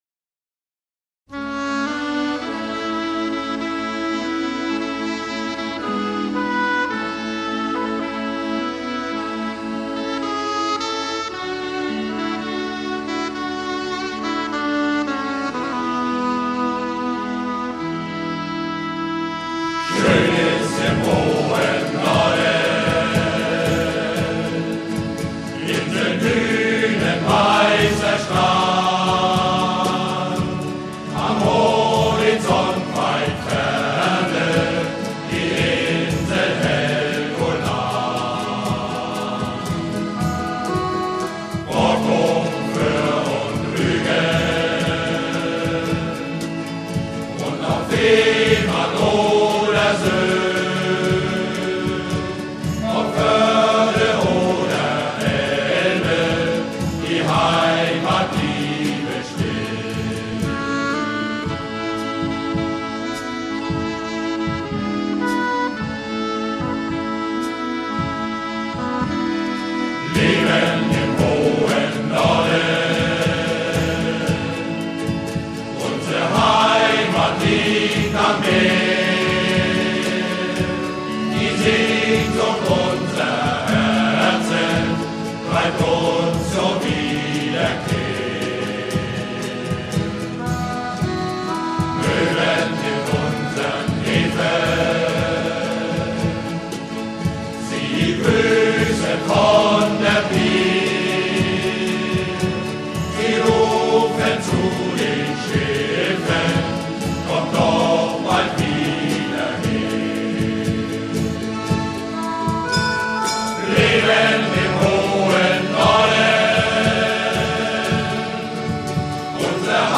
Oboe d'amore